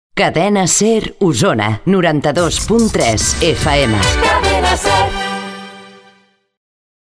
Identificació i freqüència
Banda FM